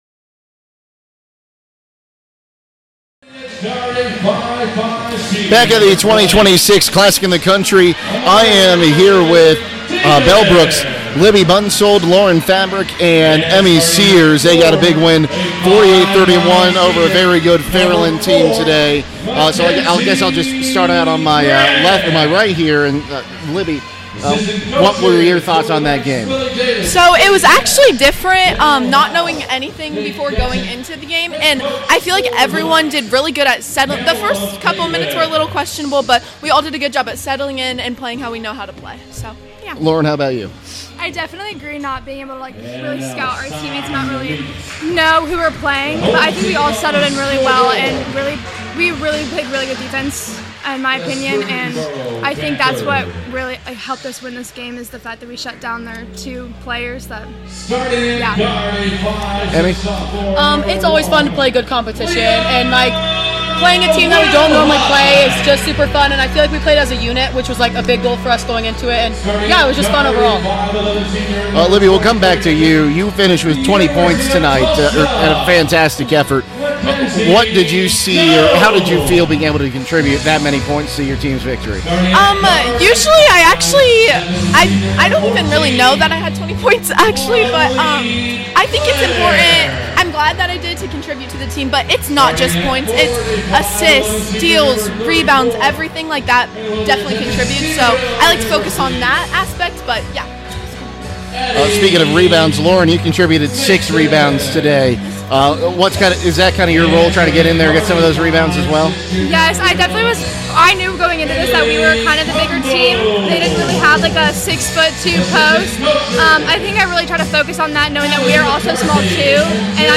CLASSIC 2026 – FAIRLAND PLAYER INTERVIEWS